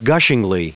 Prononciation du mot gushingly en anglais (fichier audio)
Prononciation du mot : gushingly